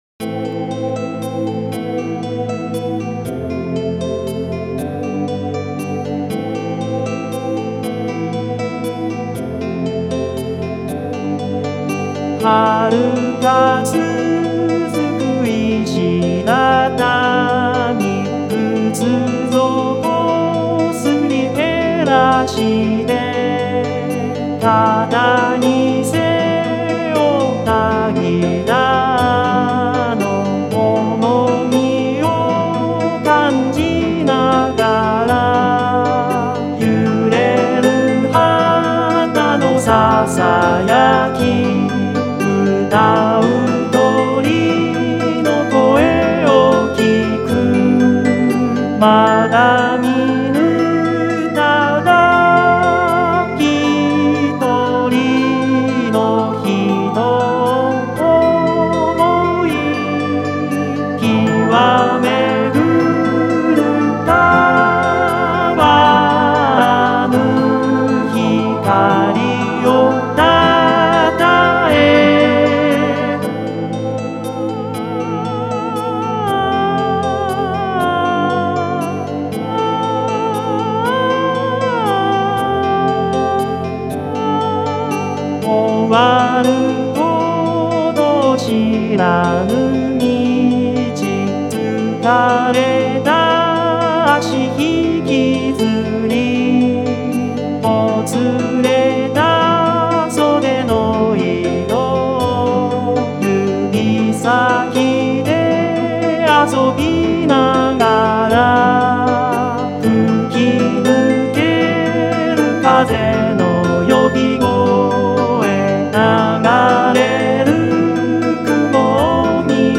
歌もの（ＶＯＣＡＬＯＩＤを使用したもの）
ギターが弾けないので歌わせた。